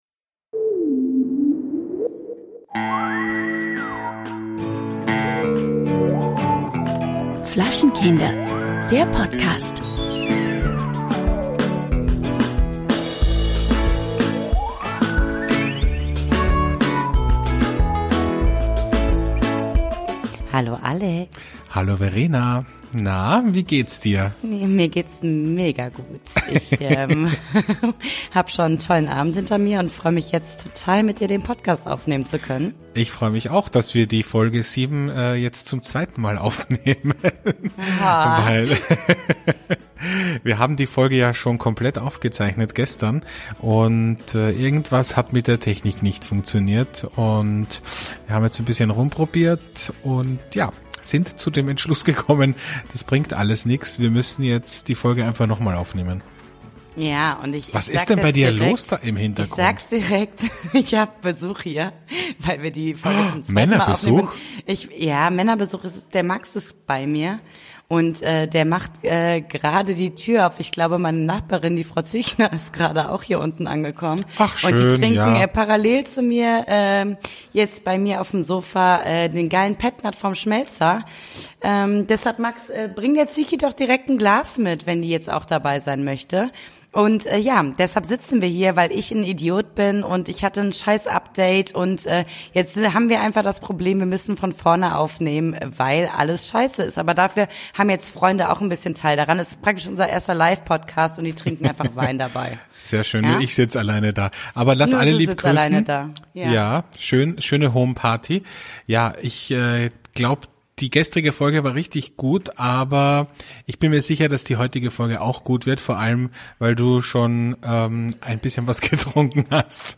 Warum wir uns betrinken - die Live-Folge ~ Flaschenkinder Podcast